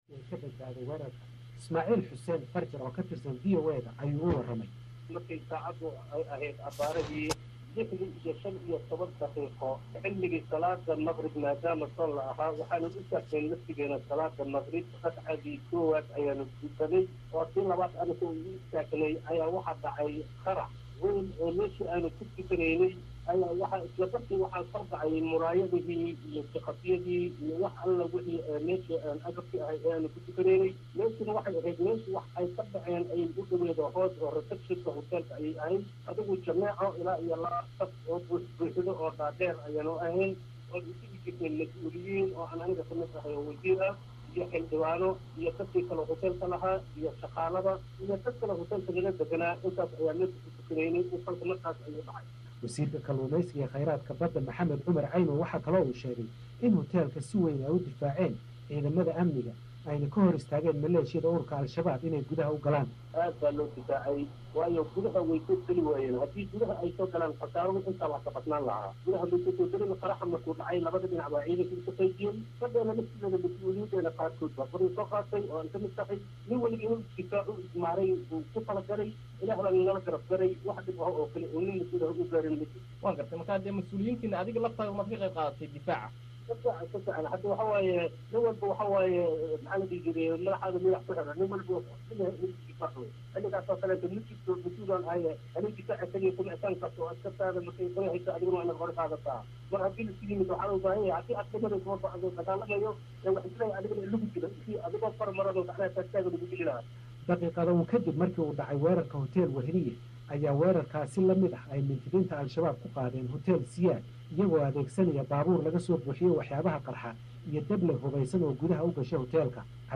Masuuliyiin ku jiray huteeladii argagixisadu gashay oo sheegay in iyaguna ka qaybqaateen difaaca huteelka: MAQAL